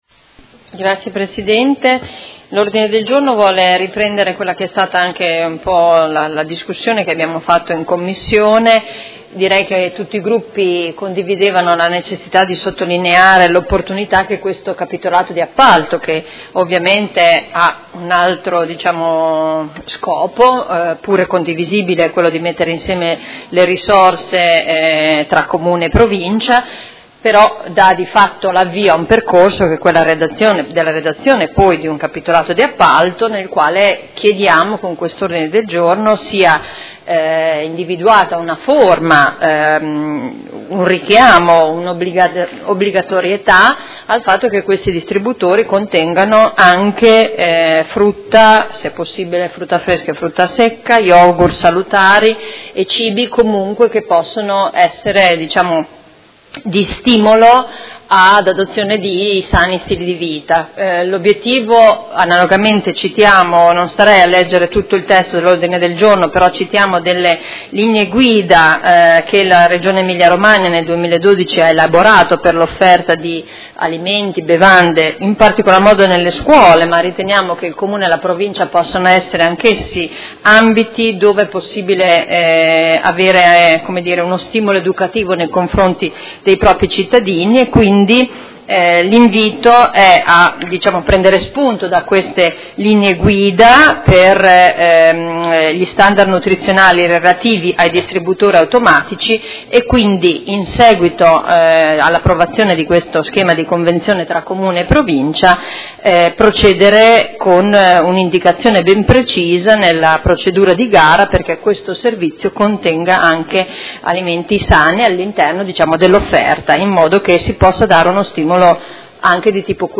Seduta del 20/07/2017 Presenta Odg 111945. Inserimento alimenti salutari nel capitolato di appalto del nuovo bando per assegnazione distributori automatici di alimenti e bevande nel Comune di Modena
Audio Consiglio Comunale